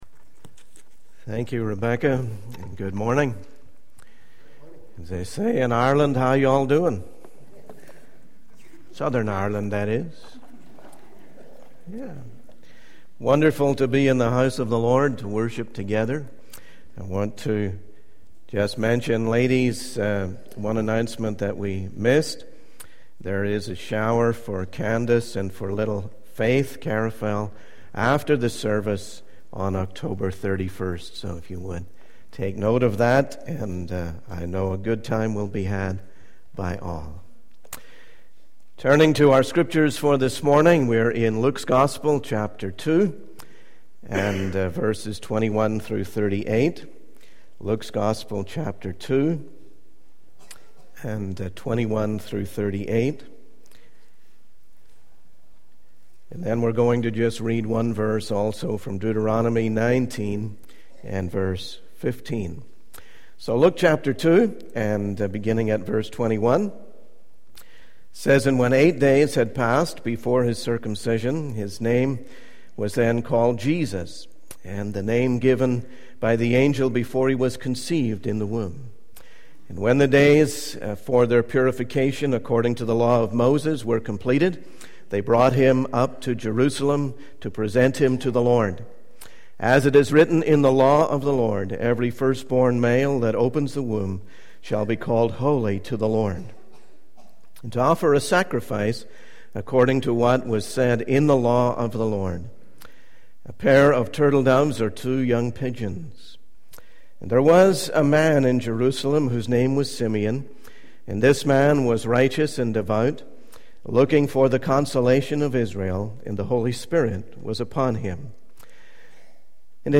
In this sermon, the speaker reflects on the story of Simeon and Anna in the Bible and their encounter with baby Jesus. The speaker emphasizes the importance of taking Christ into our lives and letting go of everything else.